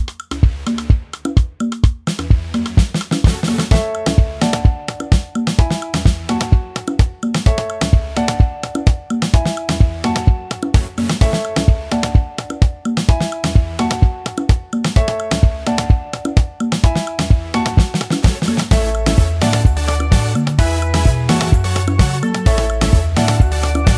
For Solo Rapper R'n'B / Hip Hop 3:24 Buy £1.50